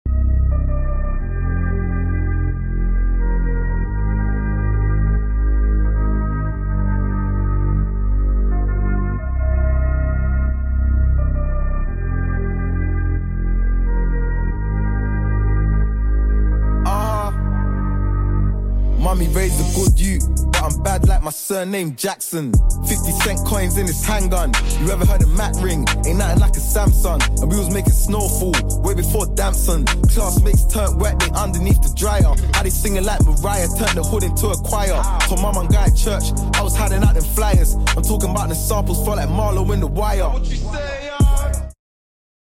Route 323 To Haldens | Wright Streetlite